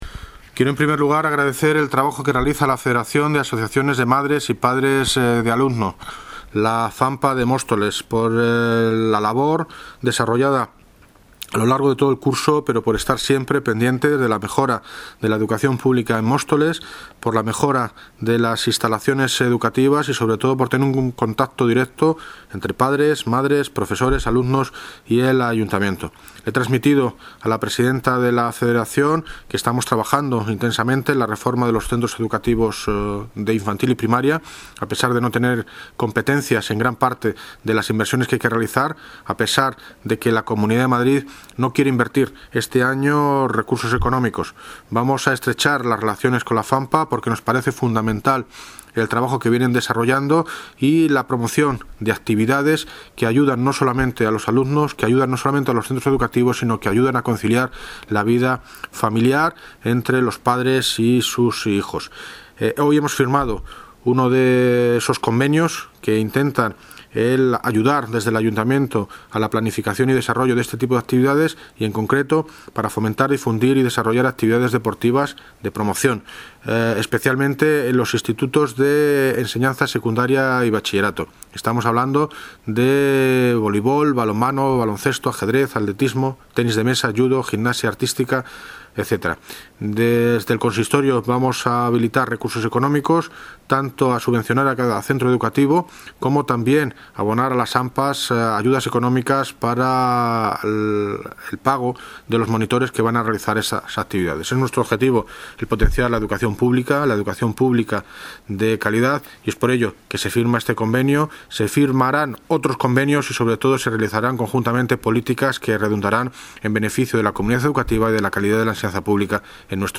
Audio - David Lucas (Alcalde de Móstoles) Sobre Convenio FAMPA